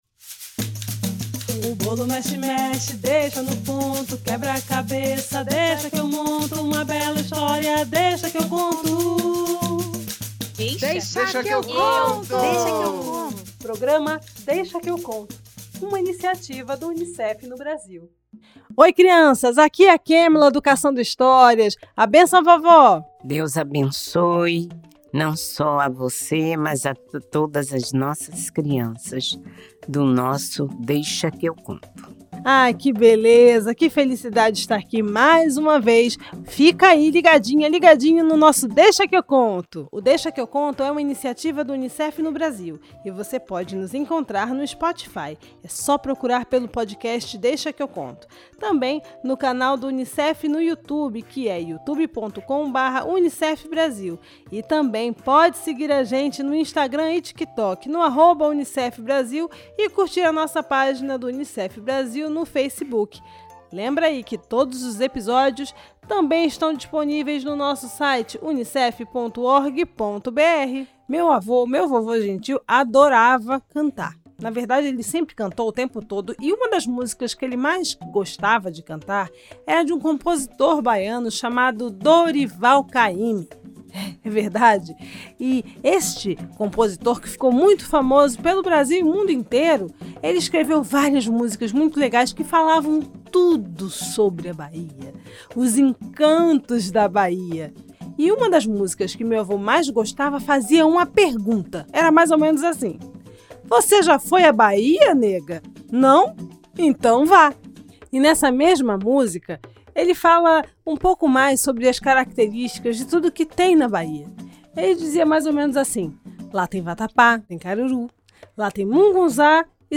Samba de roda